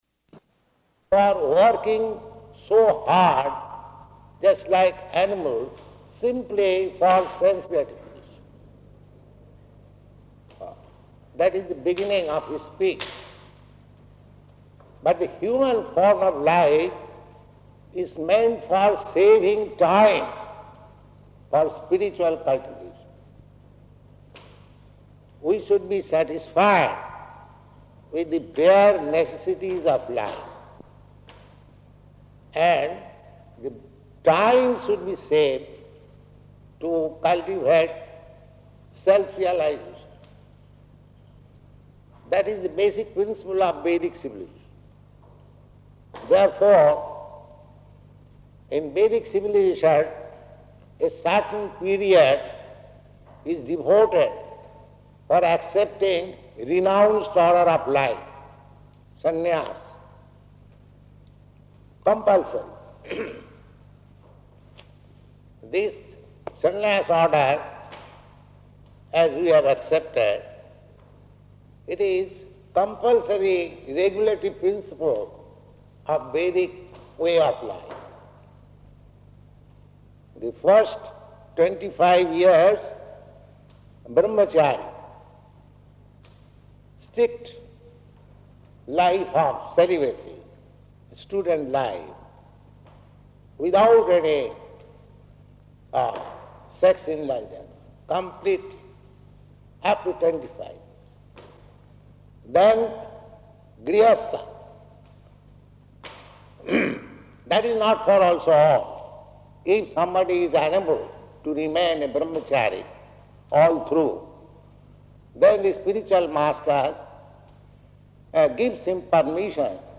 Lecture Engagement --:-- --:-- Type: Lectures and Addresses Dated: September 16th 1969 Location: London Audio file: 690916LE-LONDON.mp3 Prabhupāda: They are working so hard, just like animals, simply for sense gratification.